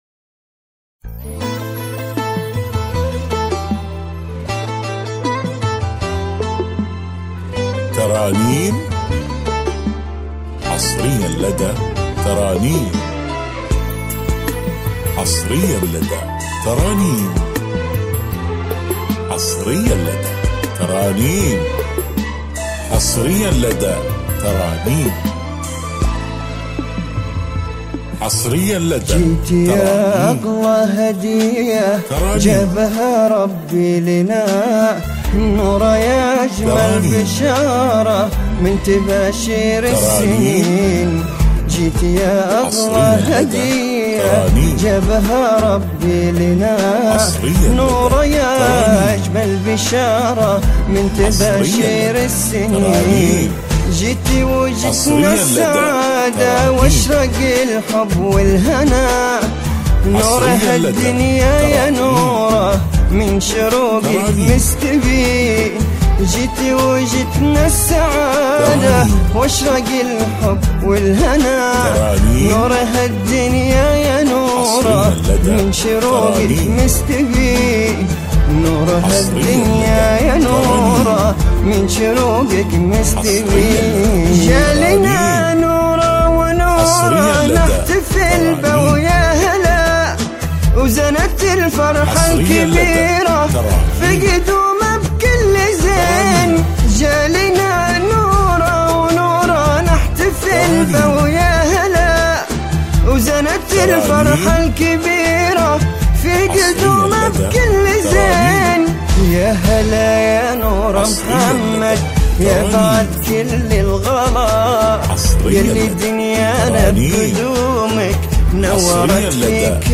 زفة مولود